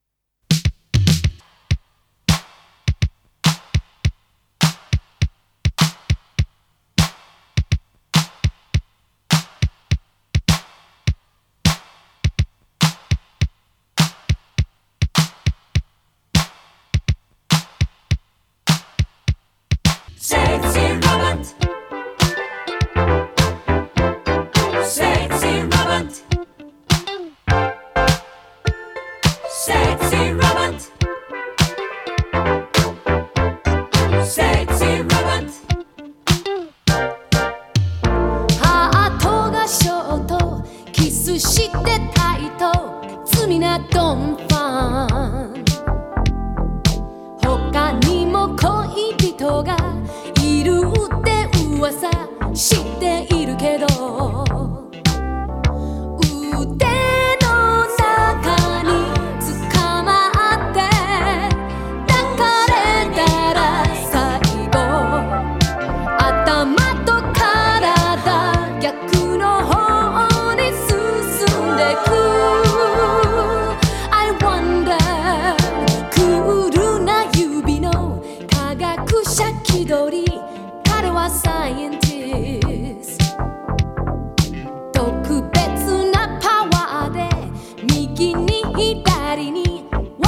CITY POP / AOR